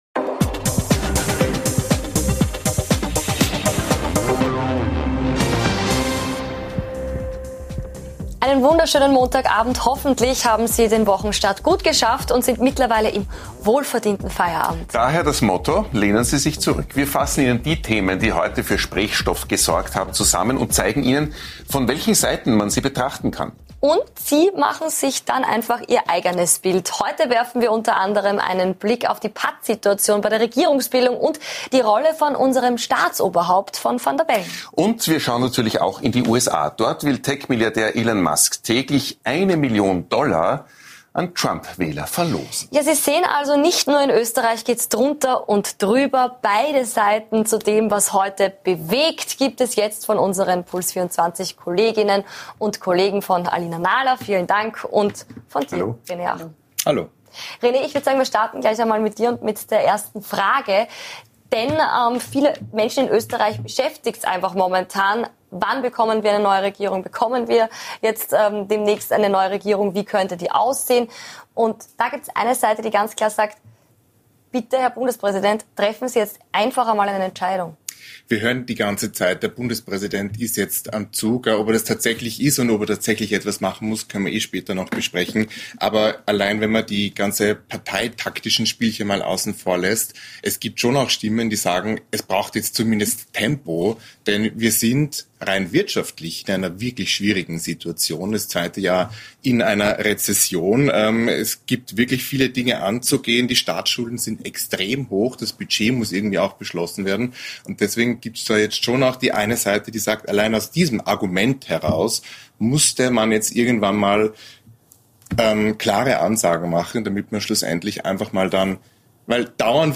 Zu Gast: Nationalratspräsident Wolfgang Sobotka (ÖVP) ~ Beide Seiten Live Podcast